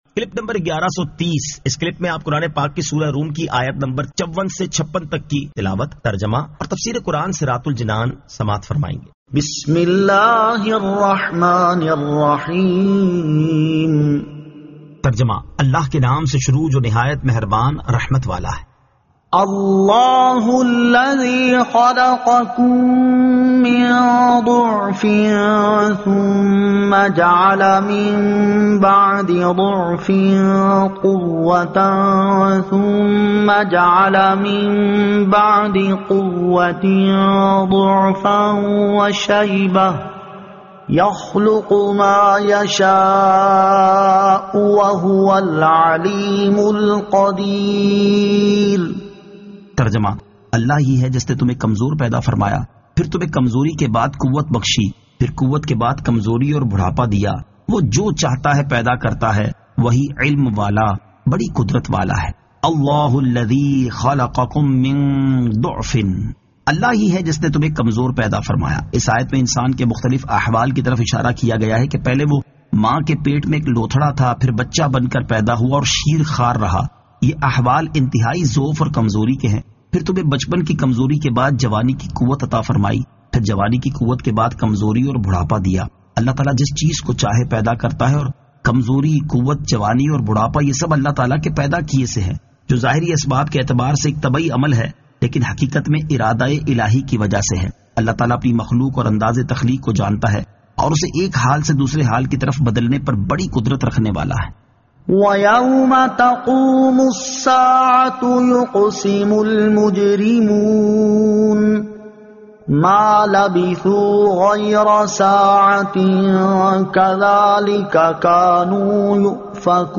Surah Ar-Rum 54 To 56 Tilawat , Tarjama , Tafseer